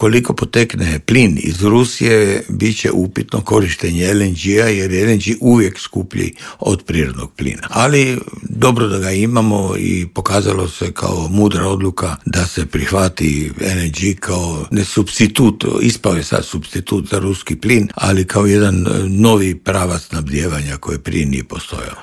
ZAGREB - Dok svijet pozorno prati hoće li doći do mirnog okončanja rata u Ukrajini, energetski stručnjak Davor Štern u Intervjuu Media servisa poručuje da bi u slučaju ublažavanja europskih sankcija trebalo dogovoriti fiksirane cijene nafte i plina.